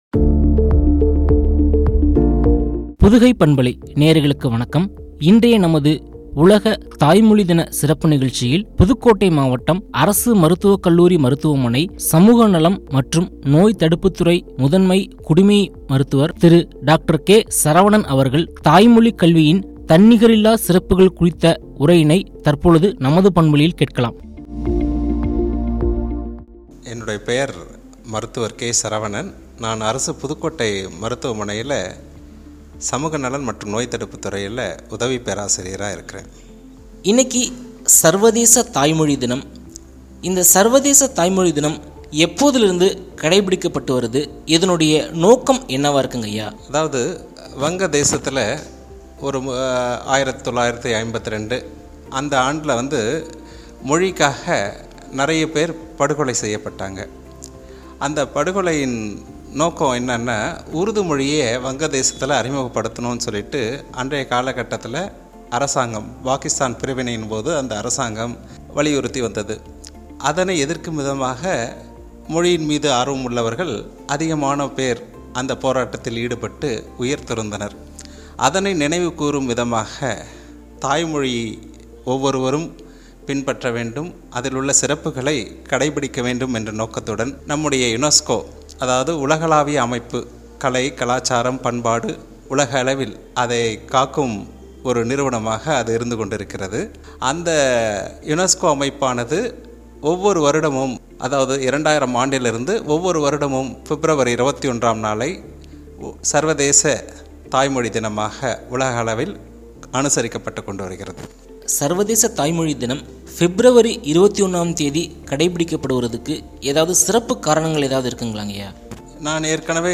” தாய்மொழிக் கல்வியின் தன்னிகரில்லா சிறப்புகள்” குறித்து உரையாடல்.